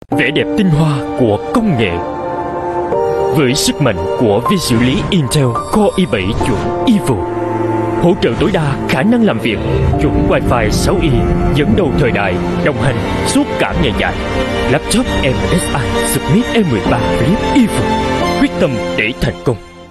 VIETNAMESE SOUTH MALE VOICES